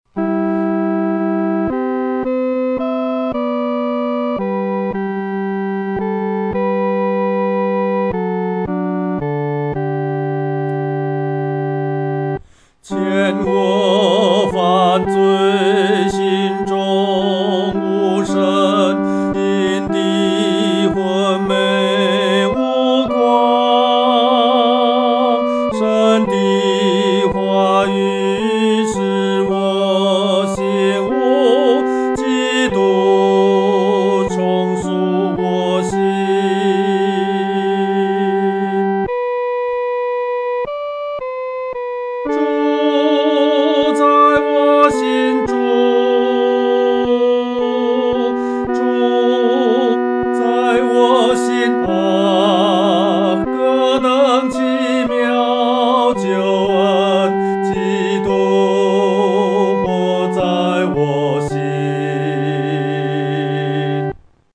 独唱（第三声）